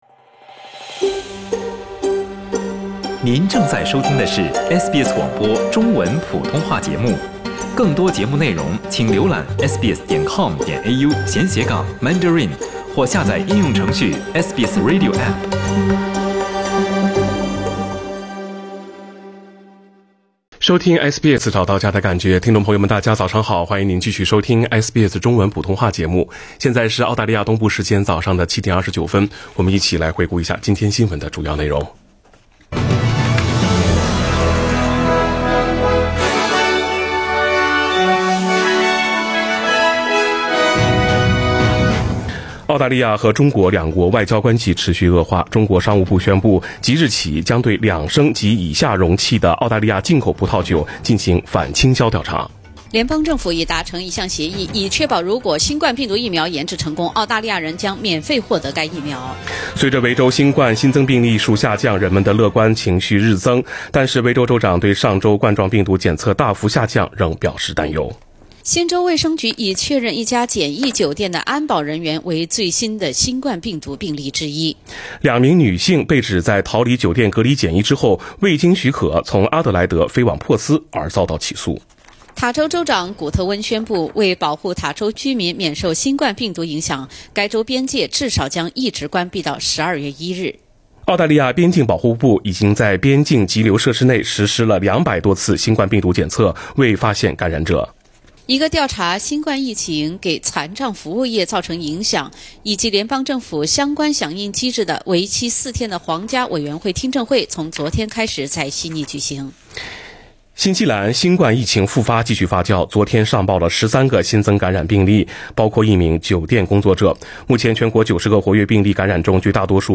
SBS早新闻（8月19日）